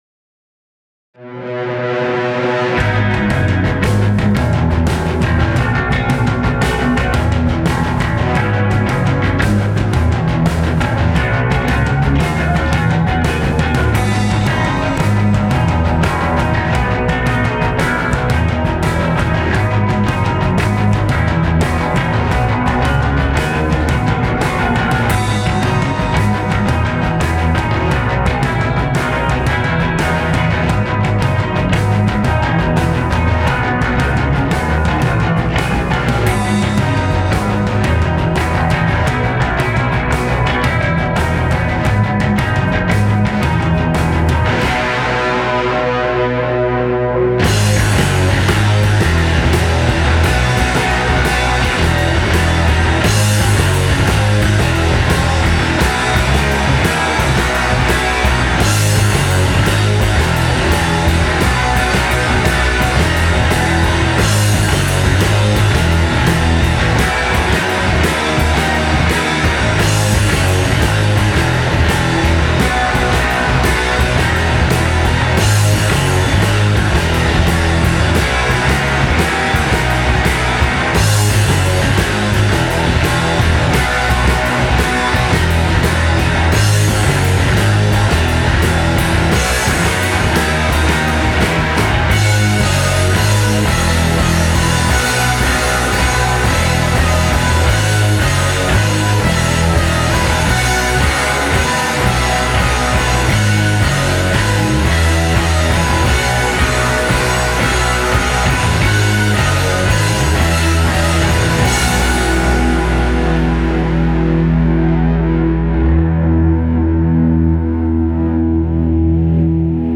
Post-rock/instrumental track
The drums are recorded in a practice room with pretty cheap condensers, so I don't think it can sound really polished. This is more of an emo sounding track which I'm thinking about uploading.